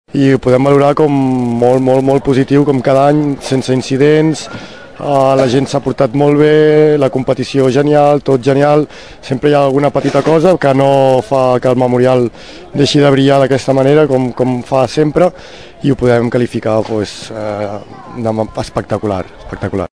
feia un primer balanç del torneig just al finalitzar l’acte de cloenda.